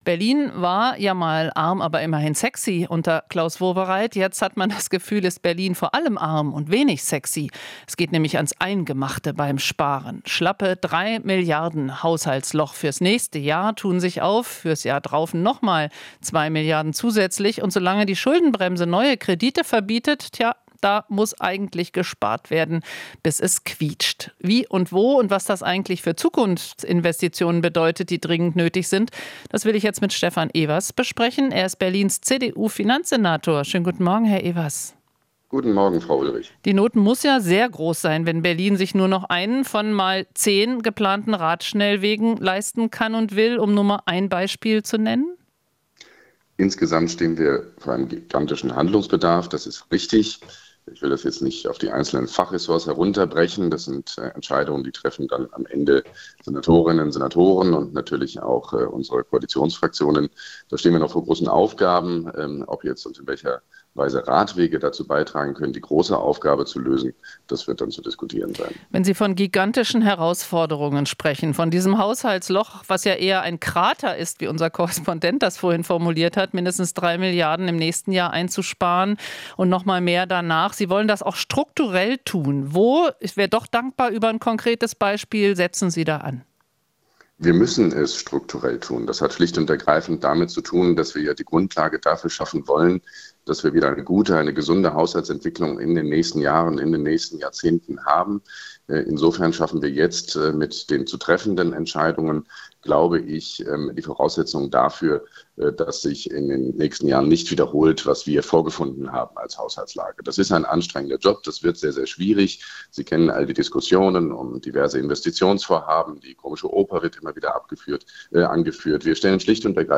Interview - Evers (CDU): "Schuldenbremse ist menschengemacht"